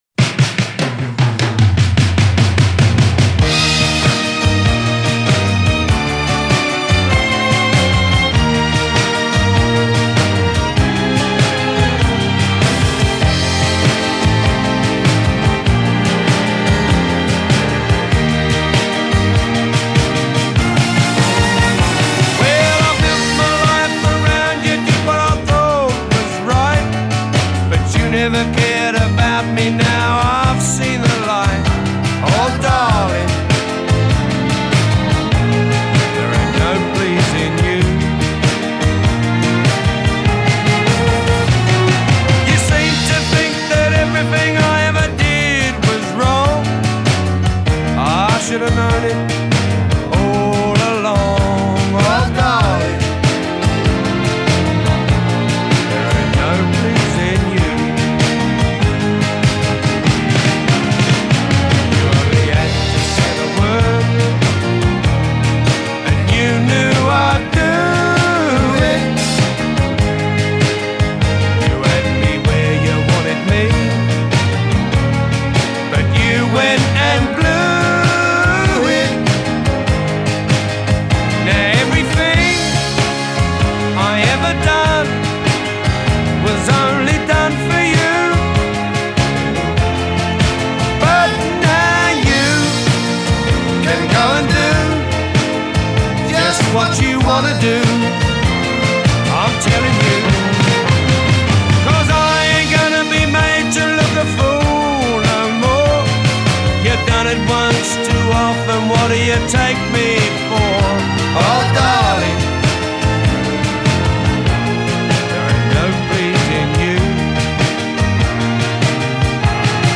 Those strings! That melancholy!
piano
Proper soul-baring stuff.
Melodrama in a minor key, they meant it, maaan.